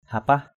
/ha-pah/